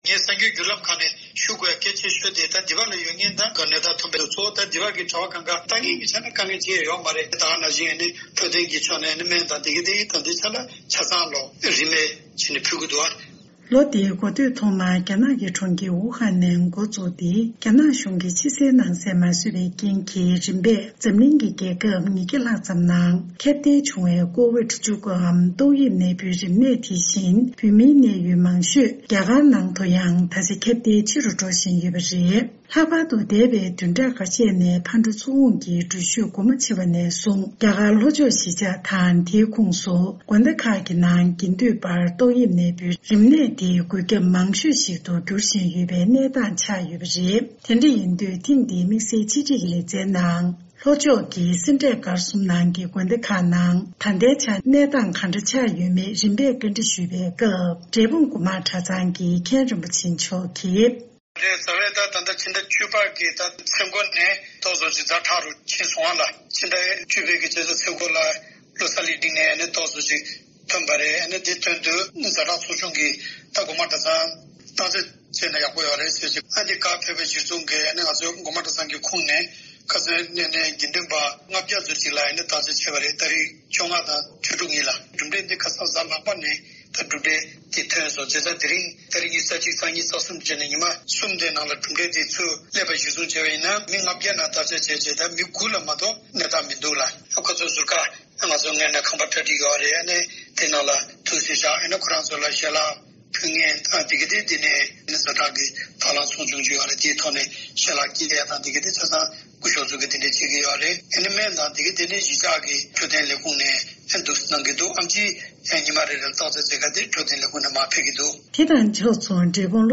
དམིགས་བསལ་ཆེད་སྒྲིག་གི་ལེ་ཚན་ནང་།